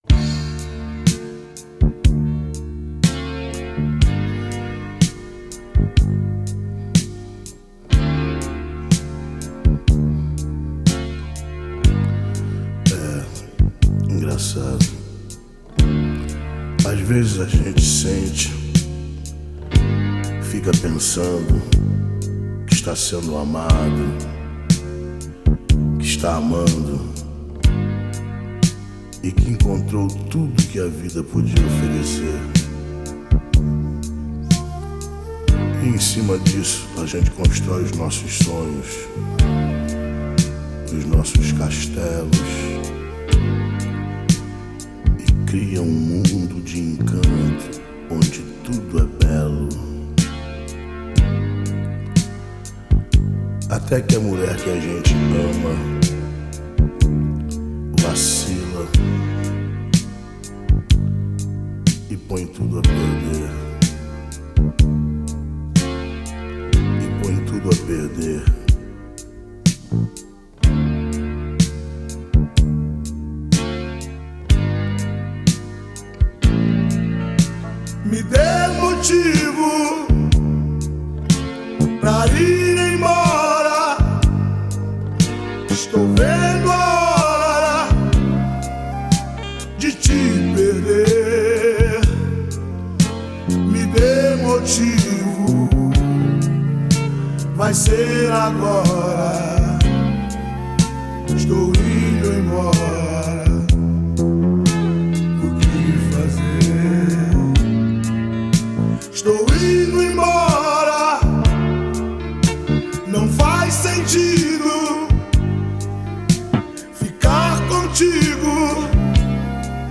2024-11-15 10:08:52 Gênero: Arrocha Views